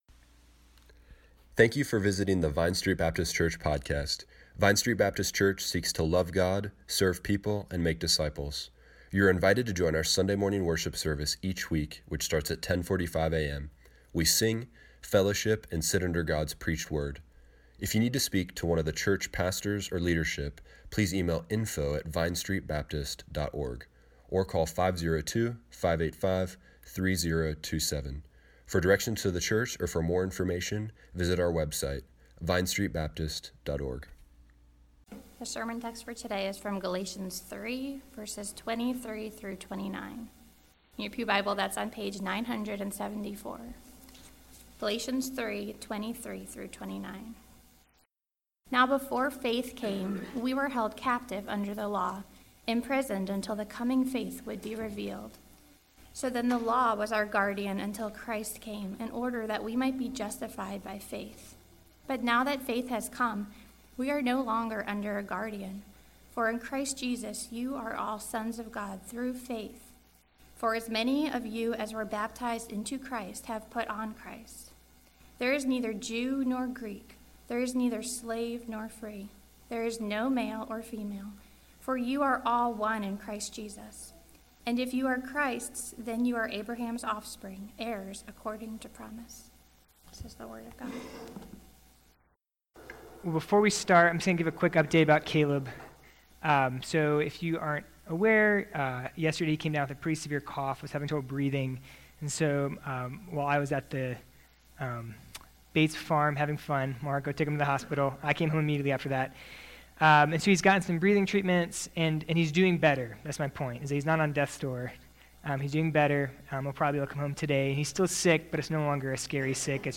2019 What does it mean to be captive under the law? The law is temporary Now we are sons of God in Christ We are one in Christ Jesus Click here to listen to the sermon online.